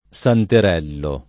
vai all'elenco alfabetico delle voci ingrandisci il carattere 100% rimpicciolisci il carattere stampa invia tramite posta elettronica codividi su Facebook santerello [ S anter $ llo ] o santarello [ S antar $ llo ] s. m. — sim. i cogn.